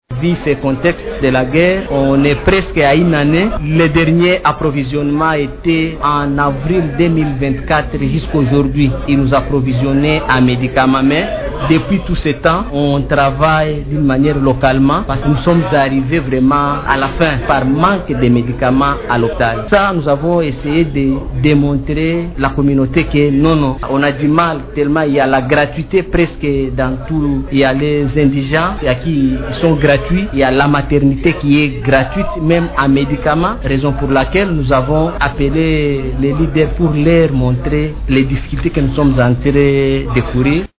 dans une interview accordée à Radio Maendeleo à travers son correspondant dans la zone.